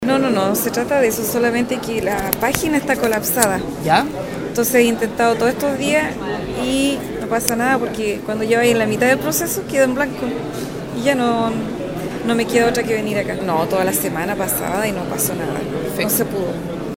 RioenLinea, acudió hasta el principal punto de pago del permiso de circulación, la Municipalidad de Valdivia.
Al respecto, una de las personas explicó que intentó en reiteradas ocasiones efectuar el trámite por internet, no pudiendo concretarlo.